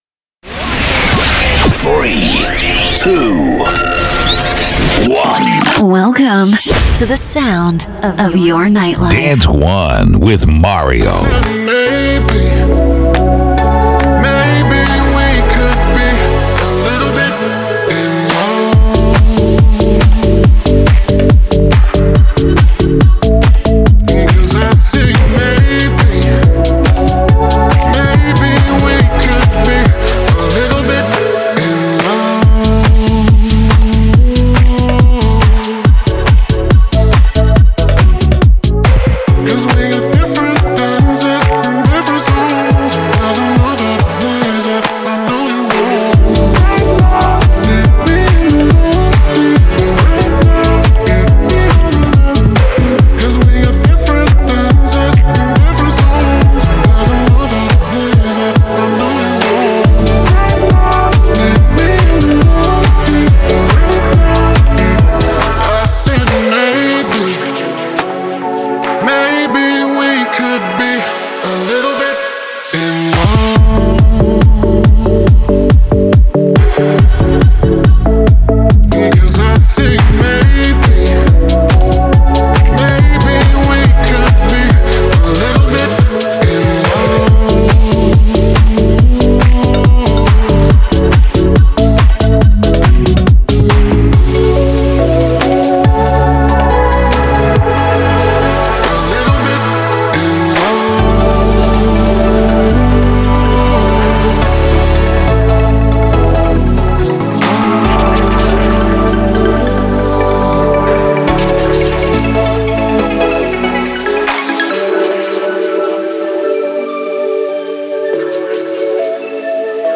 Dance anthems that rule the dance and electronic scene